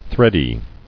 [thread·y]